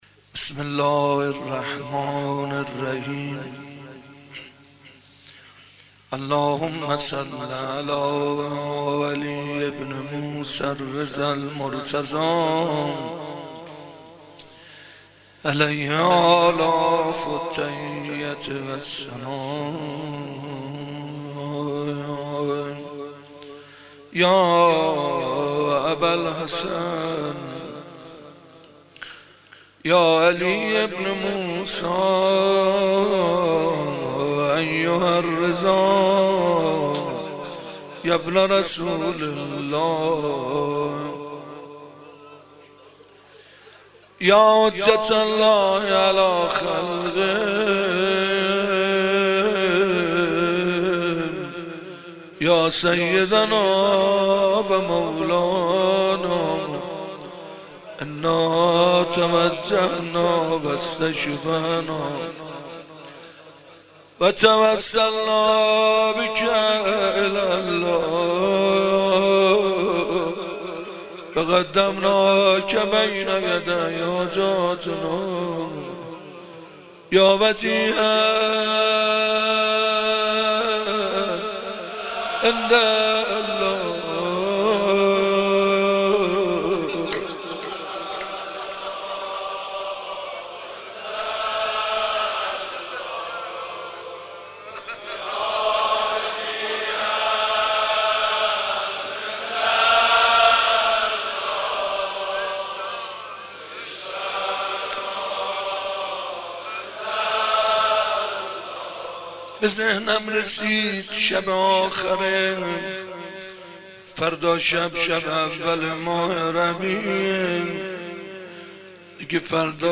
مناجات امام رضا